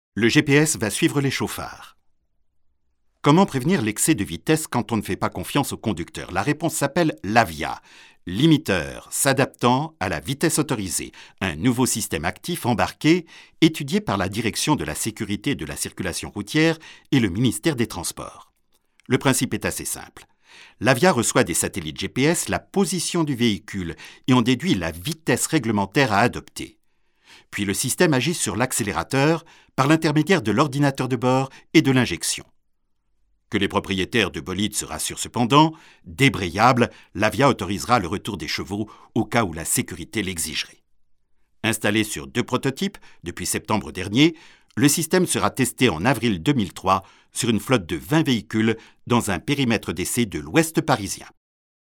Kein Dialekt
Sprechprobe: Werbung (Muttersprache):
french voice over artist.